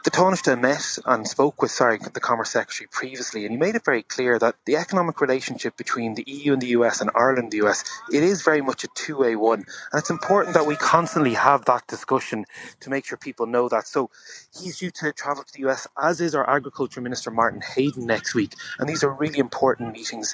Minister Neale Richmond reports